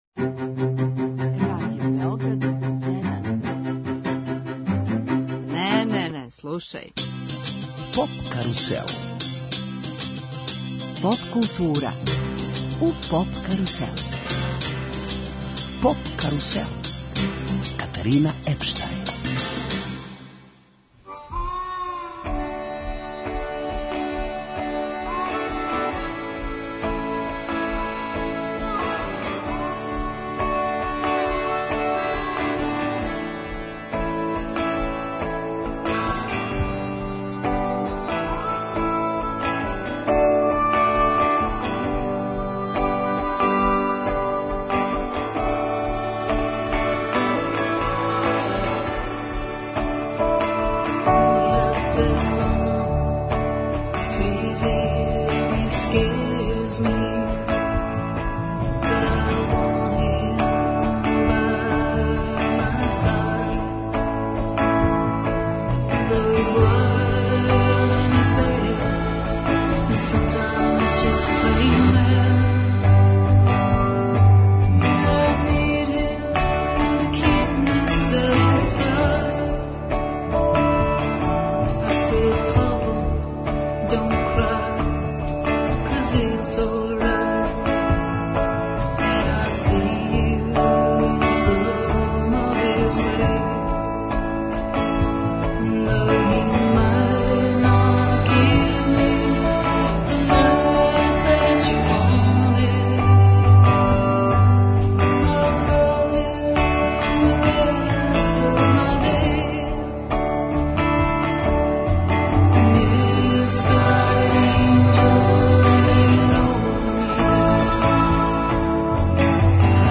Емисија из домена популарне културе.
Звук бенда чини комбинација алтернативних жанрова, пре свега indie/post-rock/shoegaze.